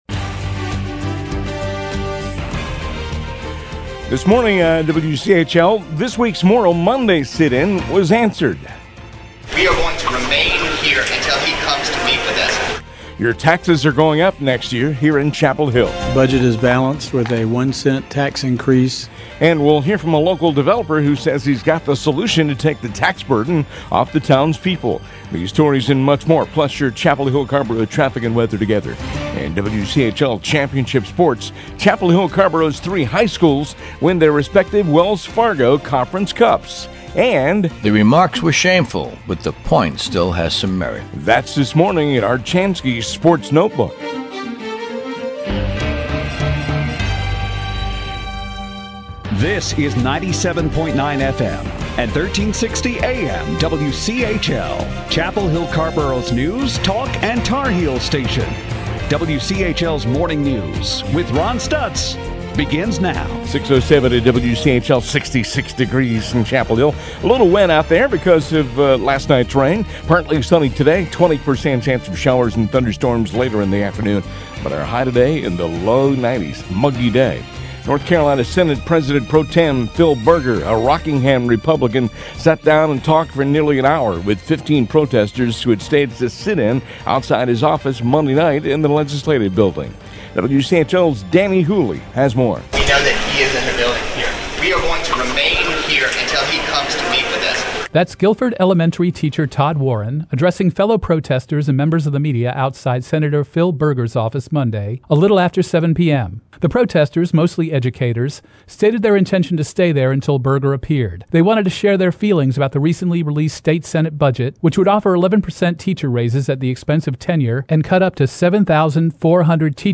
WCHL MORNING NEWS HOUR 1.mp3